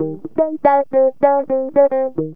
GTR 19A#M110.wav